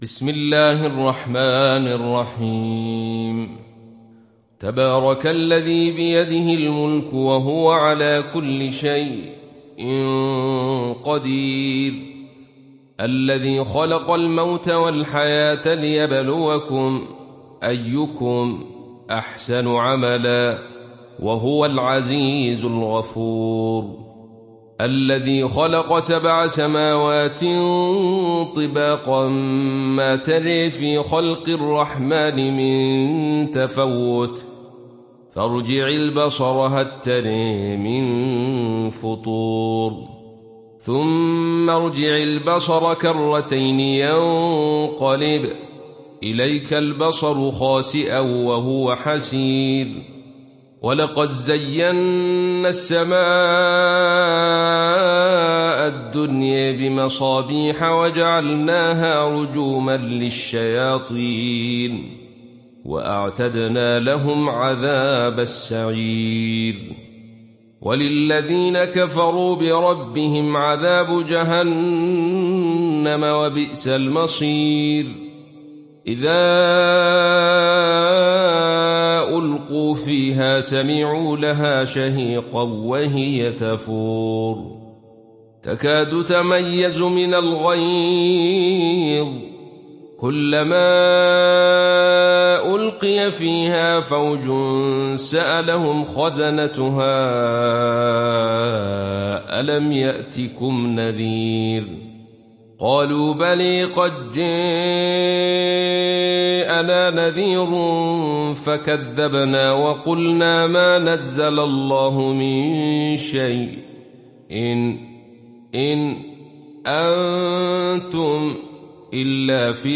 ডাউনলোড সূরা আল-মুলক Abdul Rashid Sufi